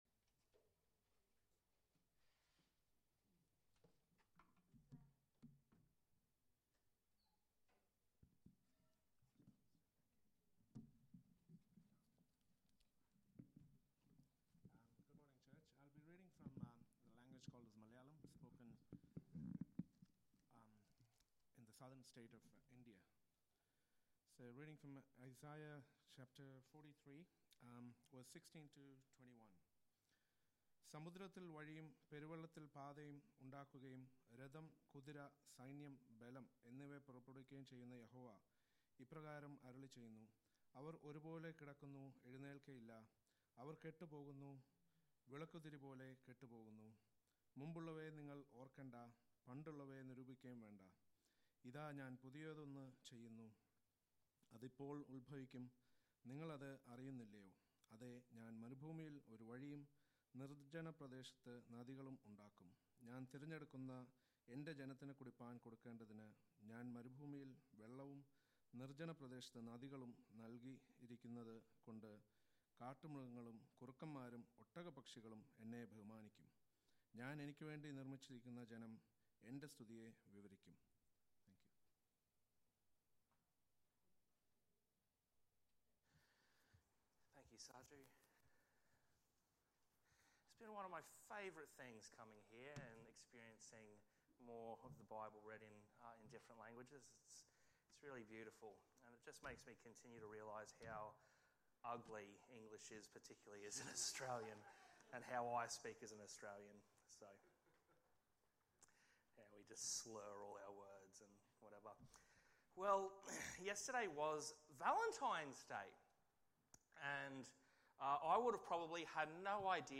Sermons | Church At The Gabba